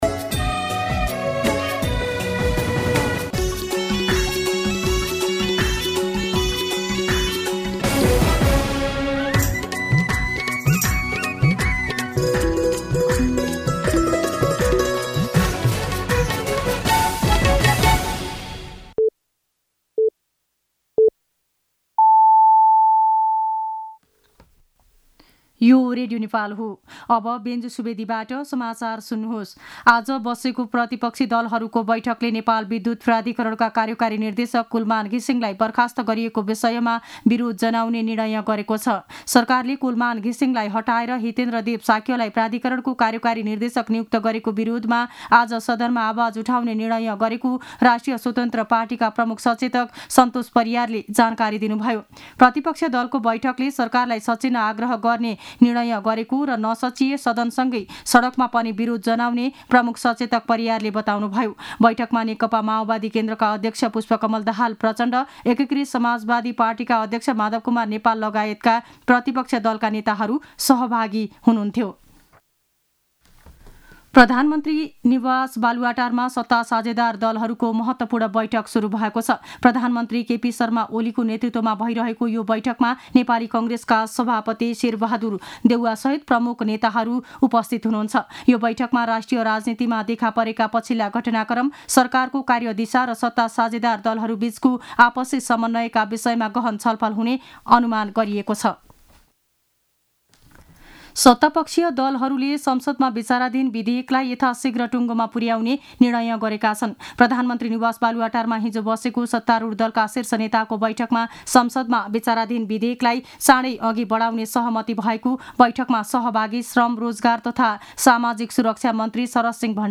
मध्यान्ह १२ बजेको नेपाली समाचार : १३ चैत , २०८१